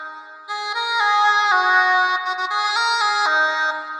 他们于2008年10月在皮特洛赫里演奏了这首曲子，在我请求他们允许录制之后。
AEVOX双耳话筒进入iriver ihp120.
标签： 双耳 音乐 苏格兰 苏格兰 风笛
声道立体声